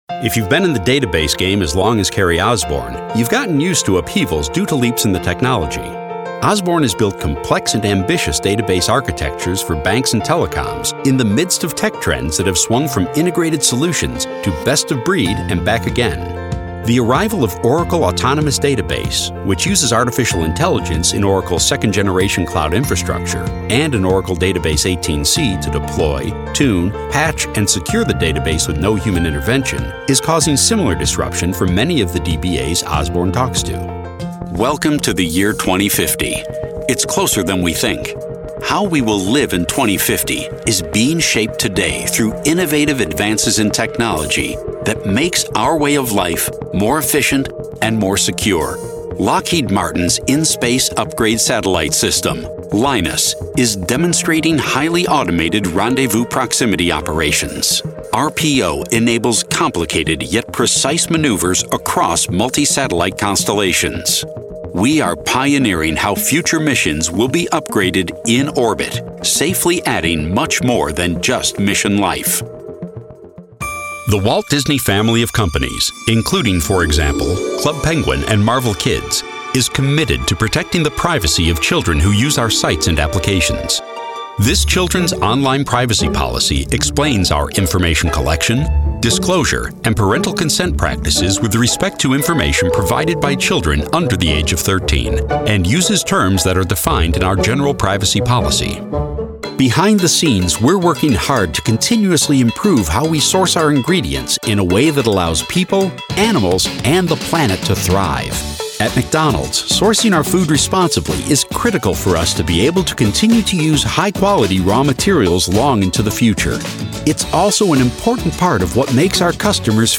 Easy-going, Real, Conversational.
Corporate